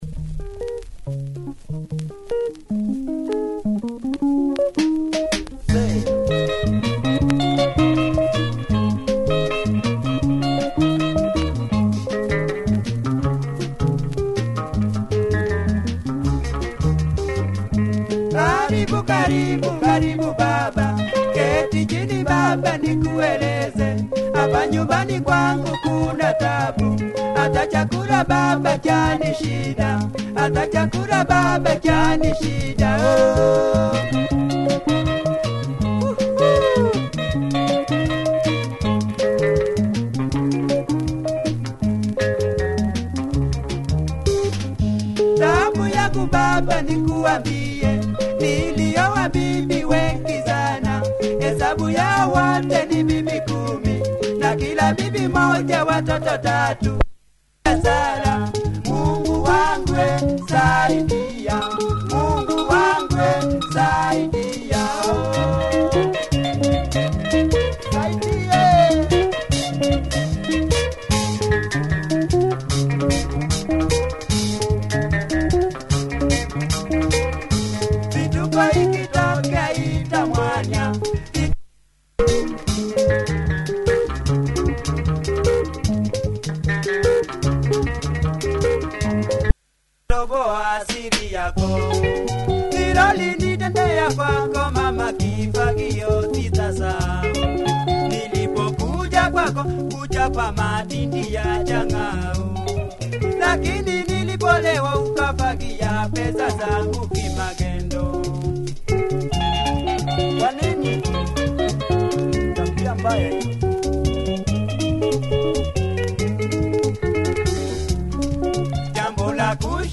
Quality Kamba benga mover